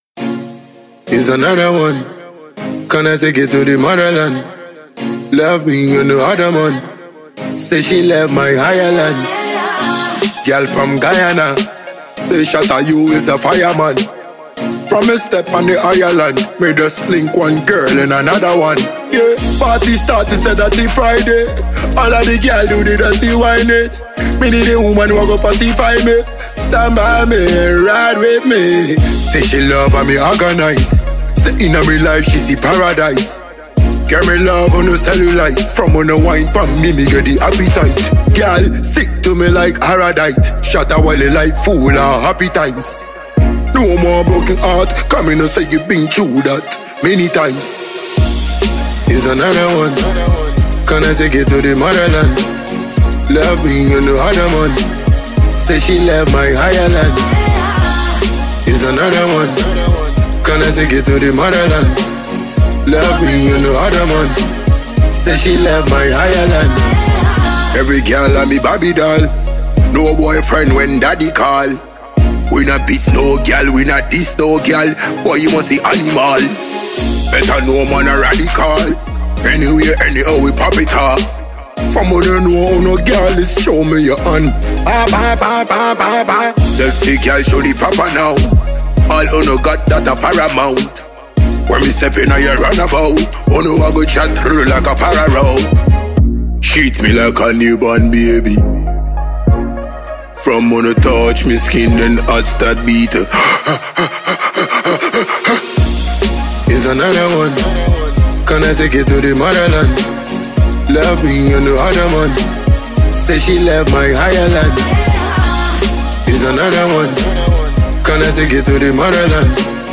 dancehall joint